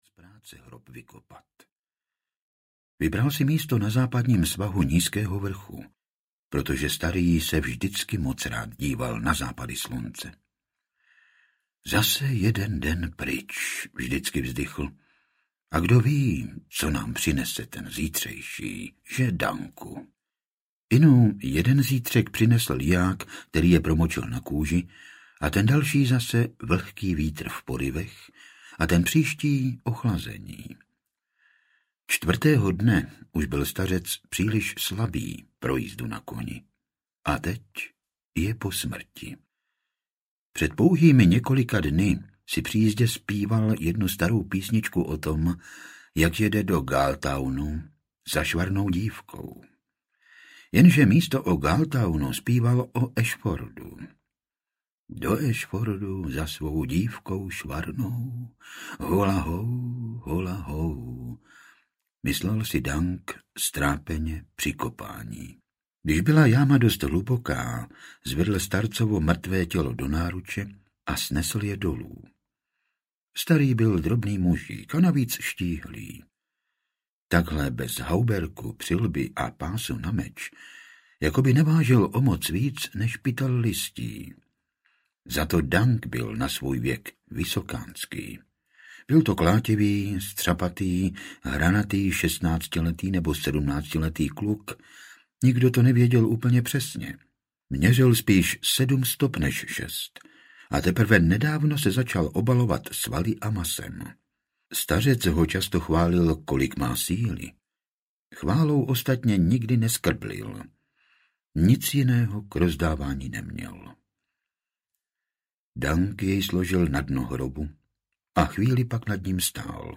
Rytíř Sedmi království audiokniha
Ukázka z knihy
• InterpretFrantišek Dočkal